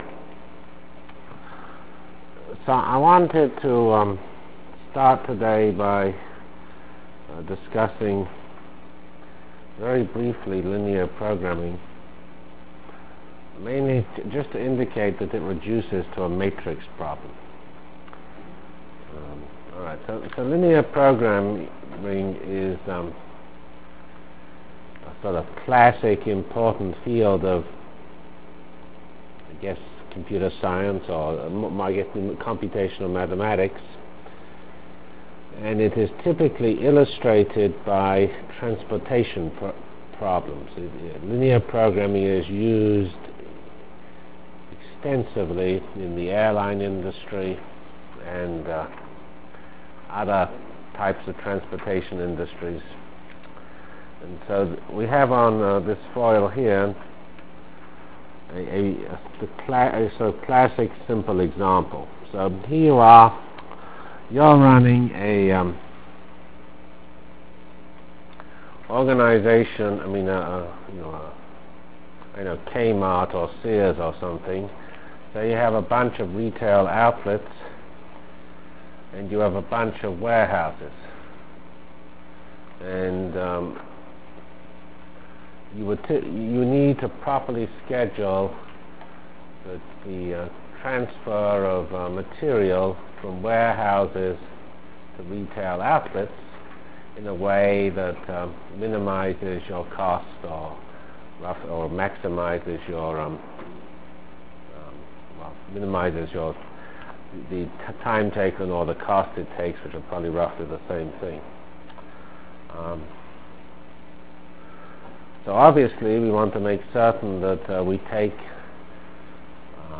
From CPS615-Linear Programming and Whirlwind Full Matrix Discussion Delivered Lectures of CPS615 Basic Simulation Track for Computational Science -- 5 Decemr 96. *